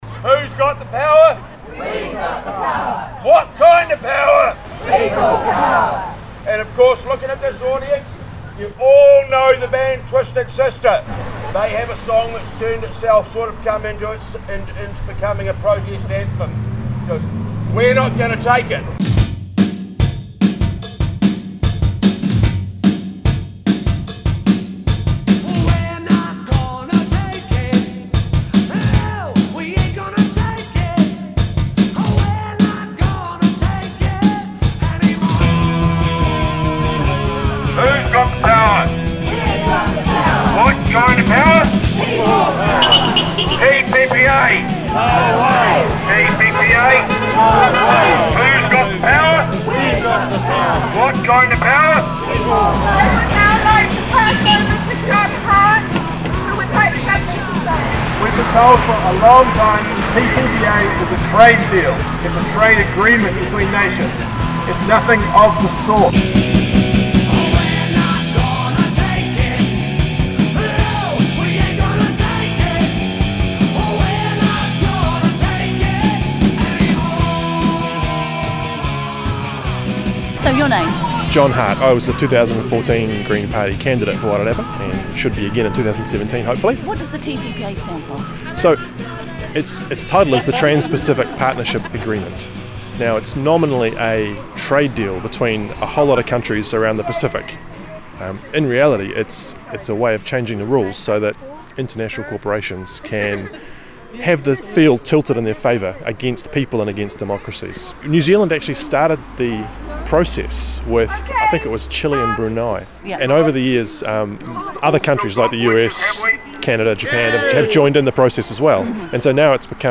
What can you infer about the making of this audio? Featherston citizens exercising their right to protest back in 2015 on the issue of the TPPA